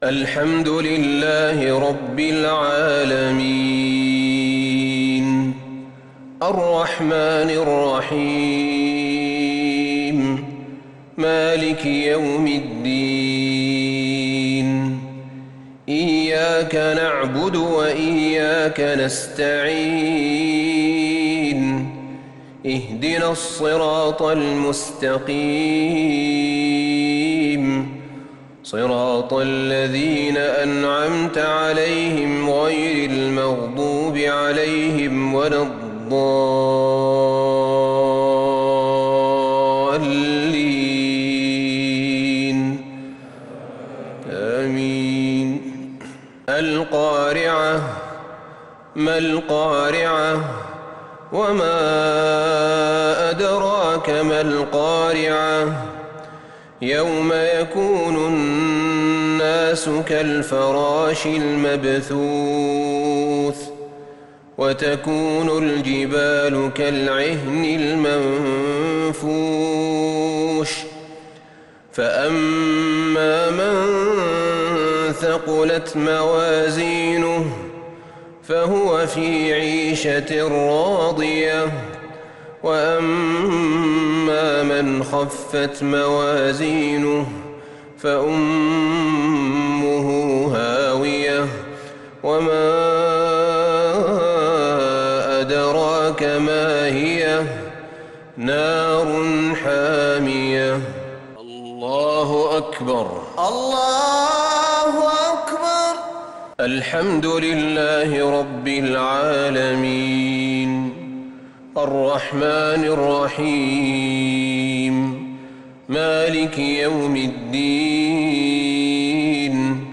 صلاة المغرب للشيخ أحمد بن طالب حميد 28 شوال 1442 هـ
تِلَاوَات الْحَرَمَيْن .